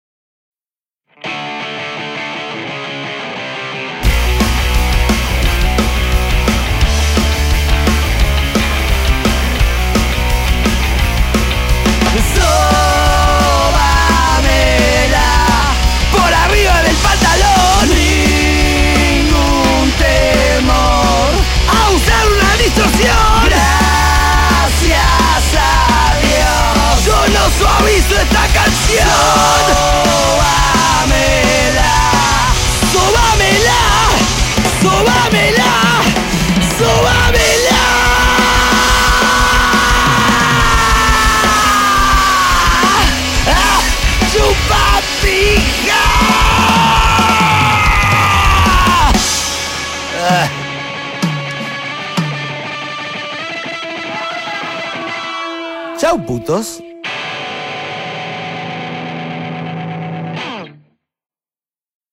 con su guitarra dotándolo de fuerza y solidez
en estudios Arizona